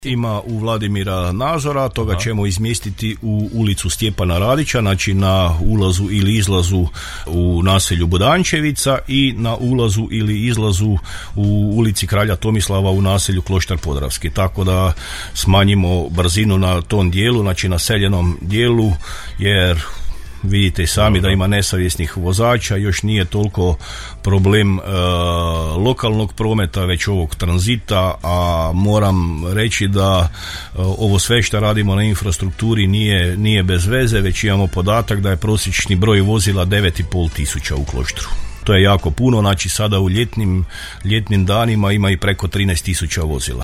Načelnik Pavlović govorio je u emisiji o aktualnim temama i događanjima na području Kloštra Podravskog i pripadajućih naselja a otkrio nam je i da će uz već postojeću kameru za nadzor brzine u Budančevici biti postavljena još jedna, a dosadašnja u centru naselja, će biti razmještena na novo mjesto: